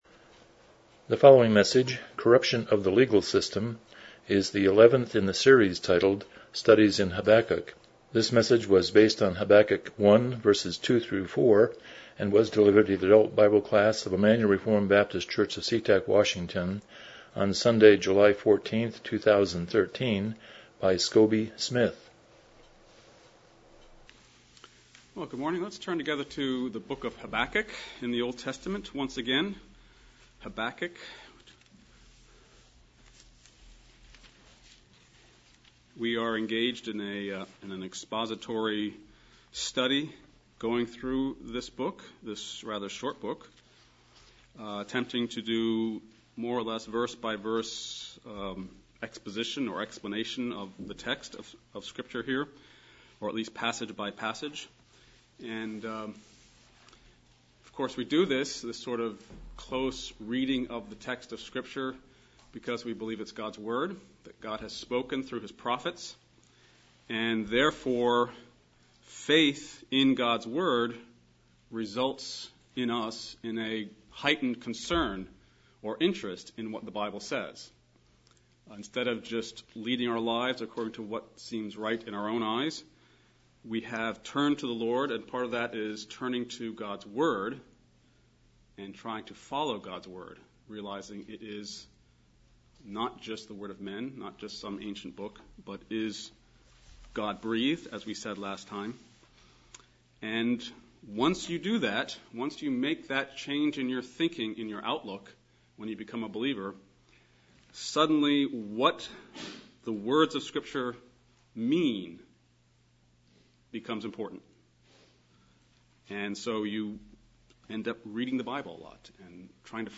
Habakkuk 1:4 Service Type: Sunday School « 31 The Sermon on the Mount